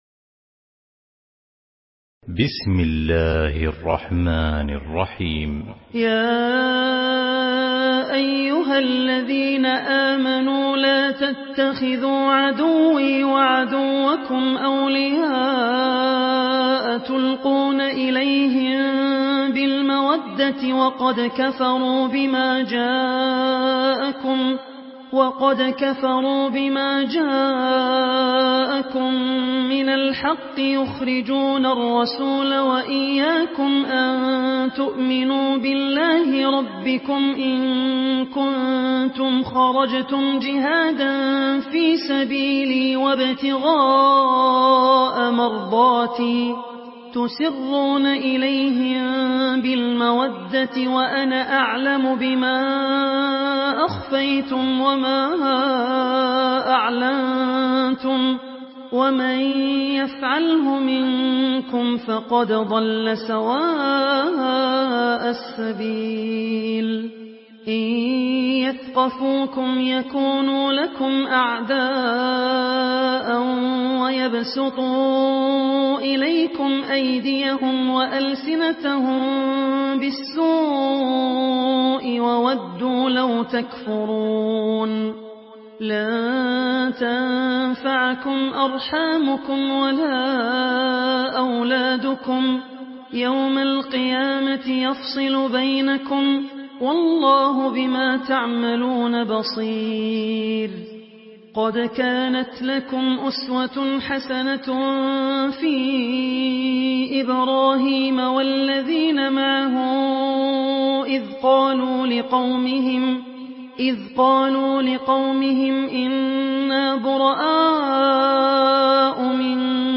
Murattal